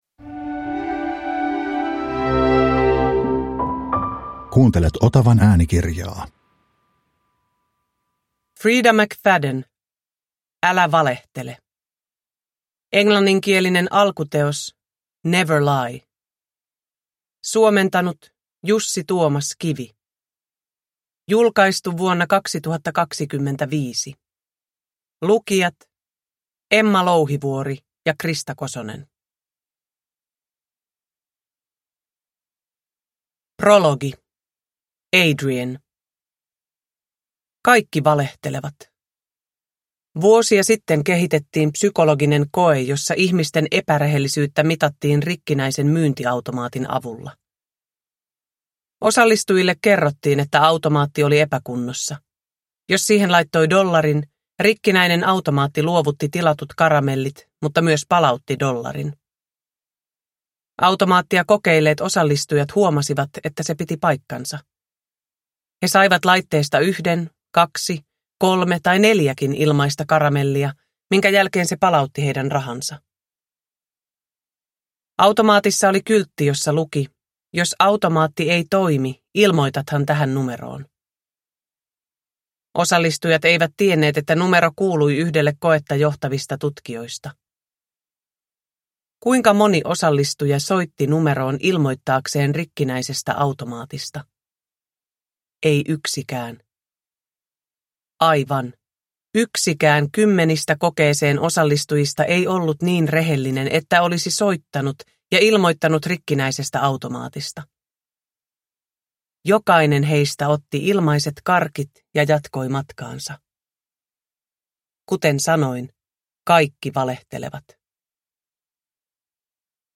Älä valehtele – Ljudbok